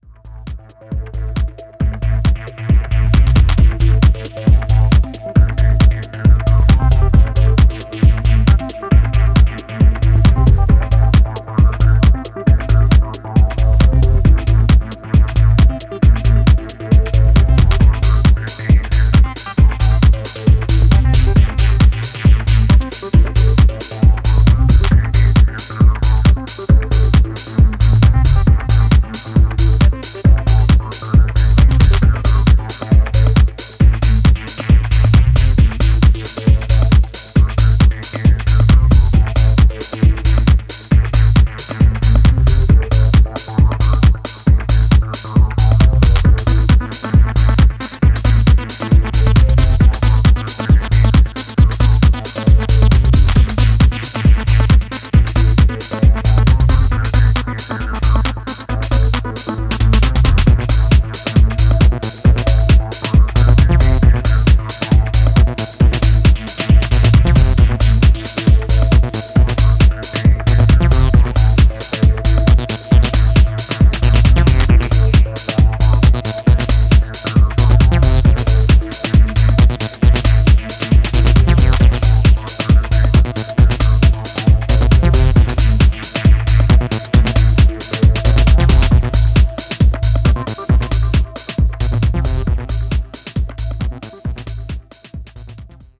トランシーなシンセワークとボトムが推進力を醸す